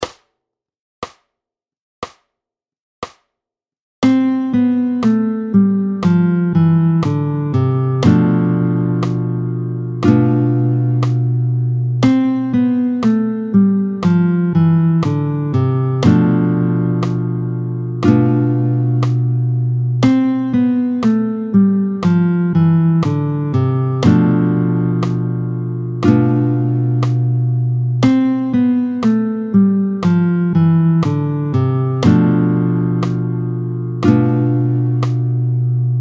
20-01 Gamme de Do majeur et accord Sim7/5b, tempo 60